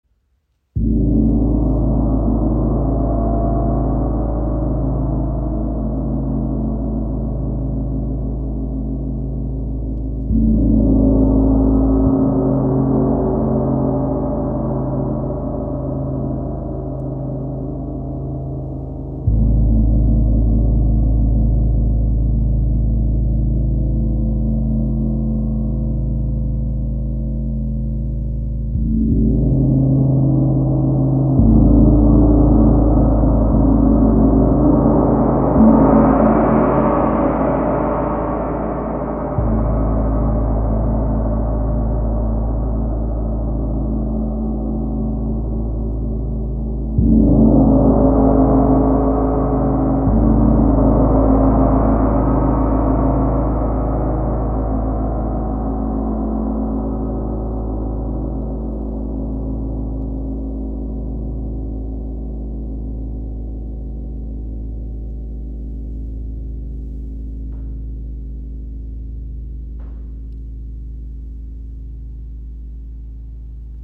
221.23 Hz (Venus, Kosmische Oktave) Ton A/Ais
• Icon Kompakter Gong mit tief resonantem, kraftvollem Klang
Mit 85 cm Durchmesser und 6,6 kg Gewicht entfaltet er einen klaren, resonanten Klang, der Herz und Geist öffnet, Energie harmonisiert und sich ideal für Meditation, Klangheilung und bewusste Energiearbeit eignet.
Universe Gong | Venus | ø 85 cm
Jeder Anschlag entfaltet eine sanfte, heilende Schwingung, die Dich in Einklang mit Dir selbst und Deiner Umgebung bringt.
• Material: Edelstahl
• Stimmung: Kosmische Oktave Venus, 221,23 Hz